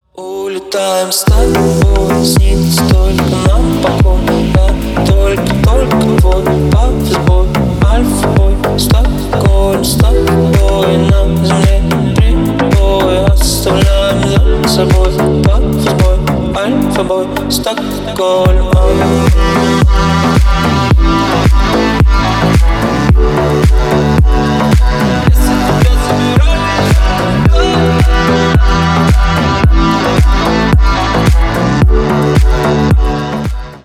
Поп Музыка
клубные # грустные